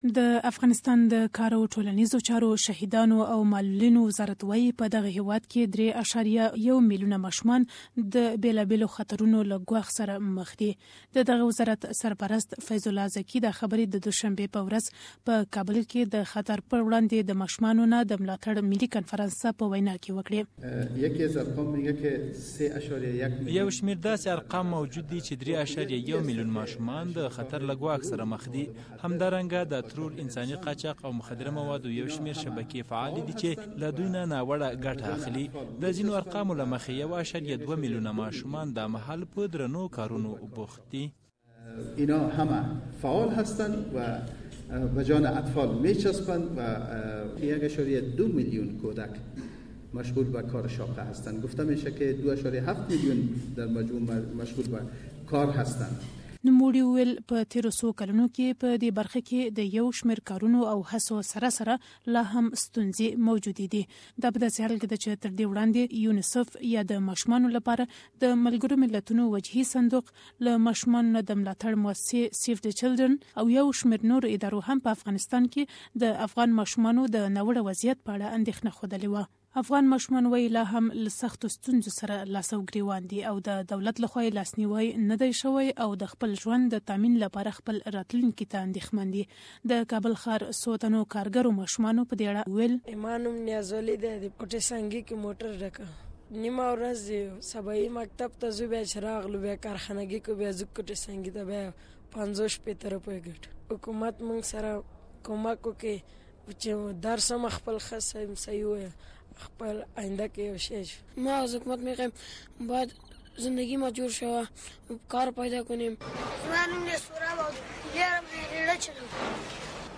SBS Pashto